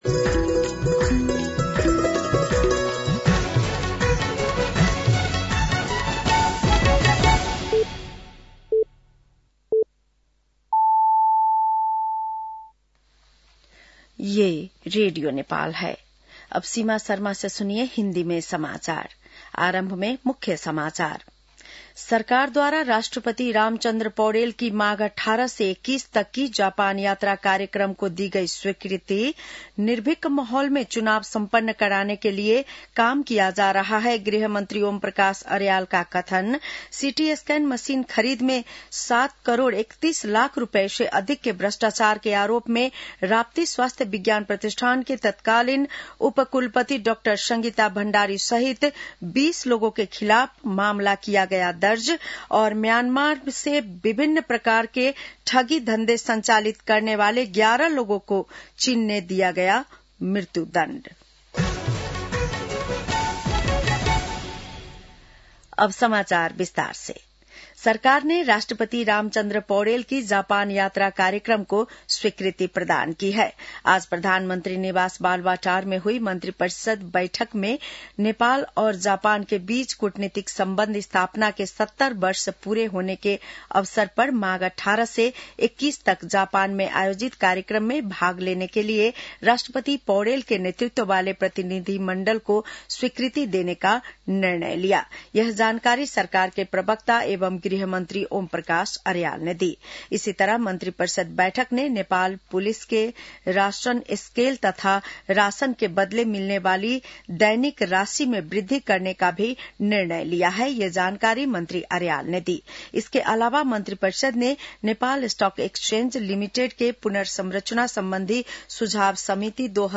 बेलुकी १० बजेको हिन्दी समाचार : १५ माघ , २०८२
10-PM-Hindi-NEWS-1-4.mp3